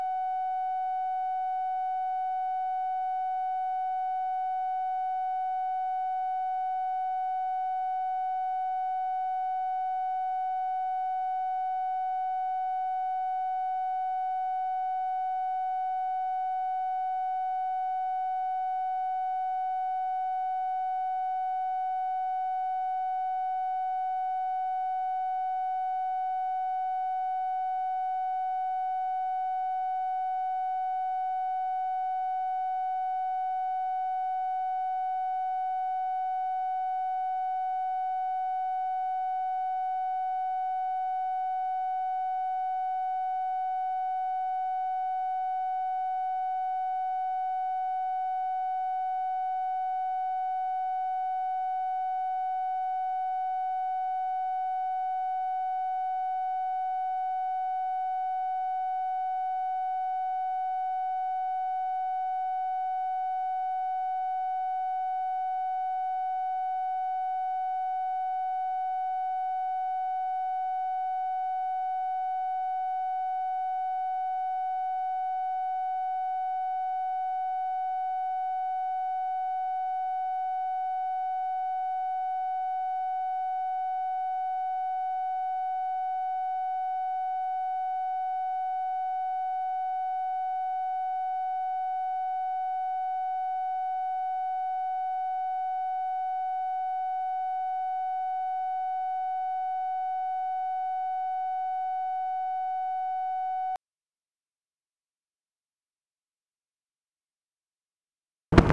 Conversation with ALEC DOUGLAS-HOME, August 10, 1964
Secret White House Tapes